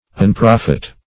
unprofit - definition of unprofit - synonyms, pronunciation, spelling from Free Dictionary
Unprofit \Un*prof"it\, n. Want of profit; unprofitableness.